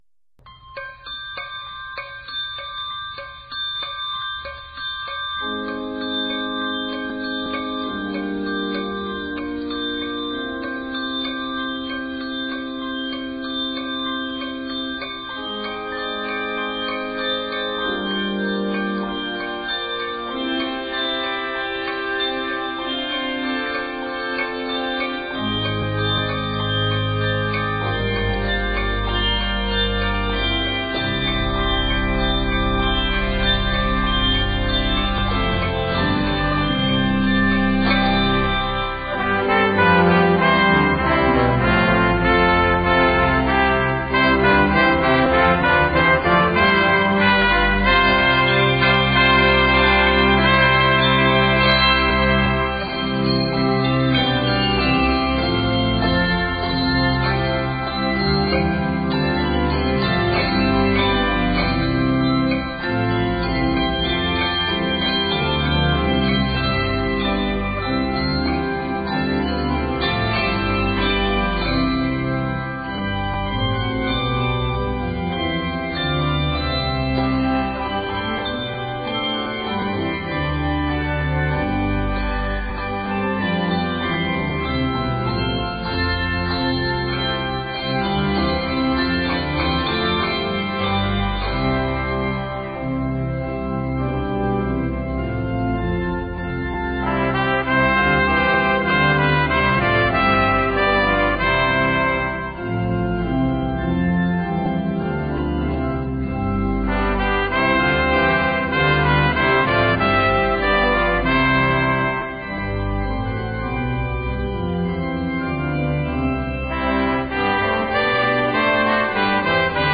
Brass parts consist of two Trumpets and two Trombones.